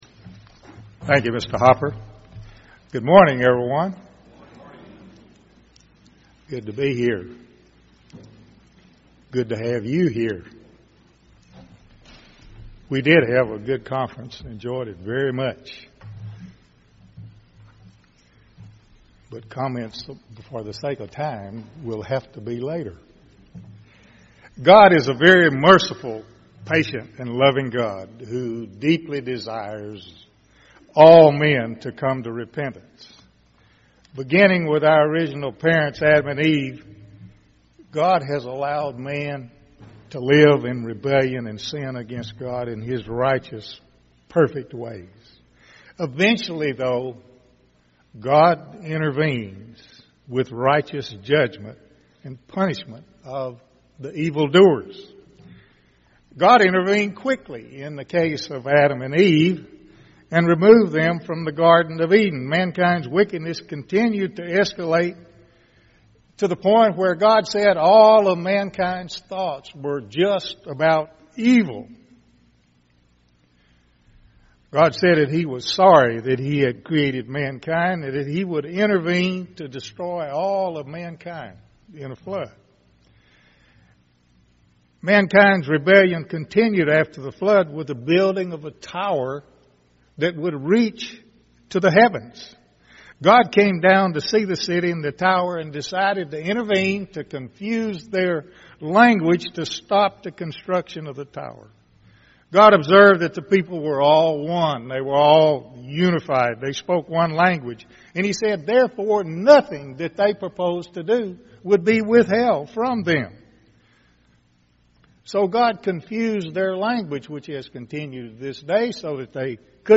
Given in Tulsa, OK